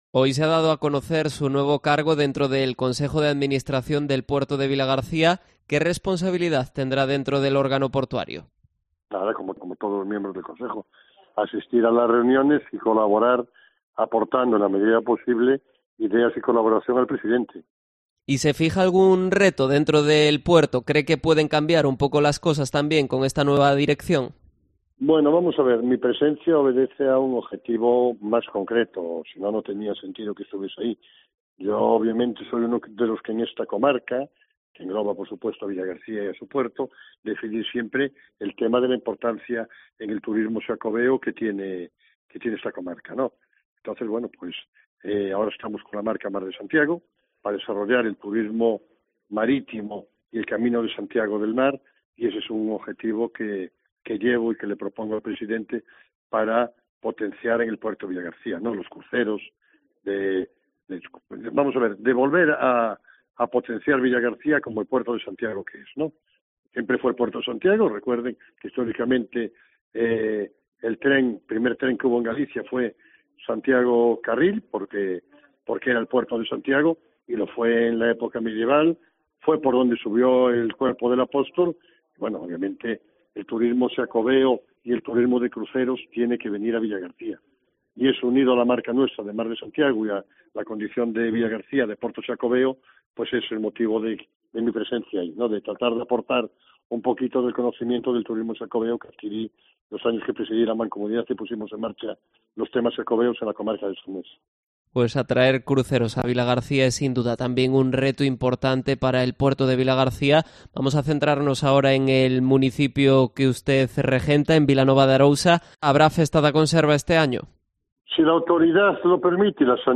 Entrevista a Gonzalo Durán, alcalde de Vilanova de Arousa